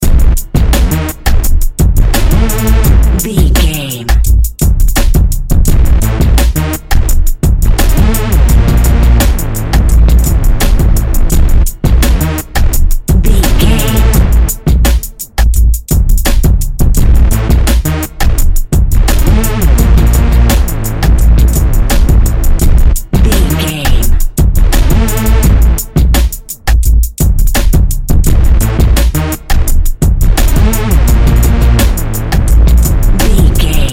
Epic / Action
Fast paced
Phrygian
intense
futuristic
energetic
driving
aggressive
dark
synthesiser
drum machine
Drum and bass
break beat
electronic
sub bass
synth leads
synth bass